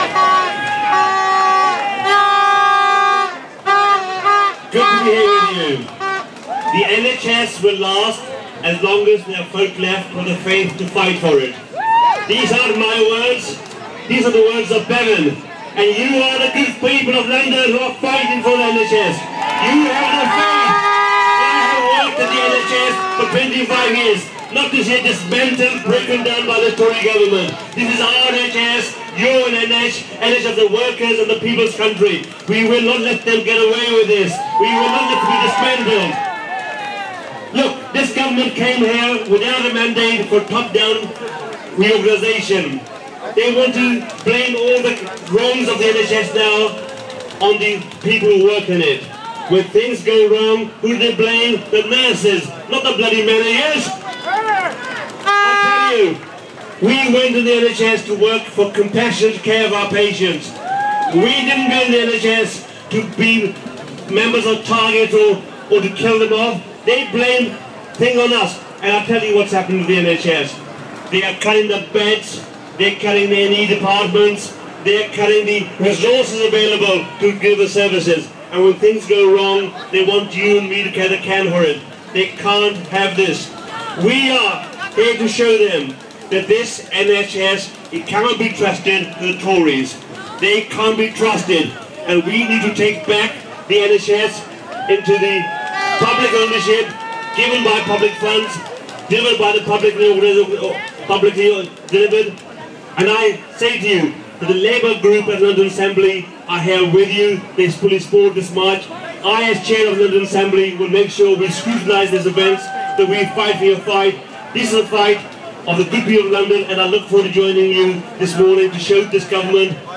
Dr Onkar Sahota speaks at Defend NHS march on South Bank